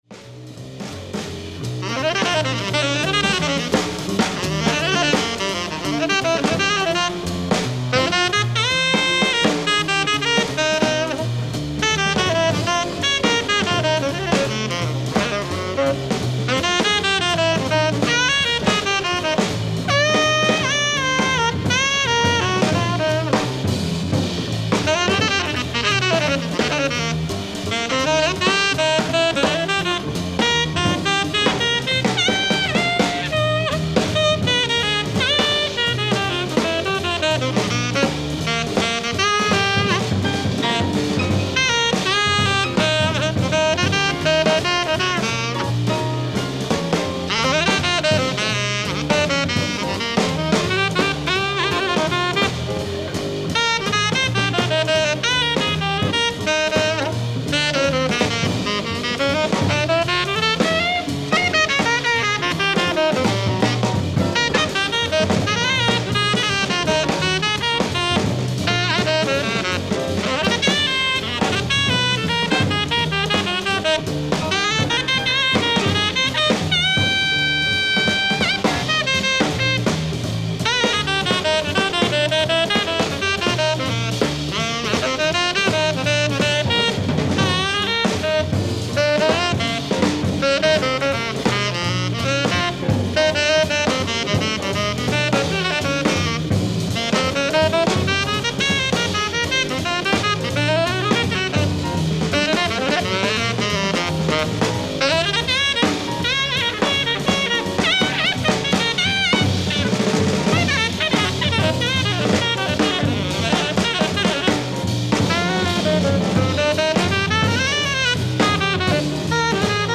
ライブ・アット・ハンブルグ、ドイツ 08/08/1975
※試聴用に実際より音質を落としています。